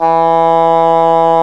WND ENGHRN09.wav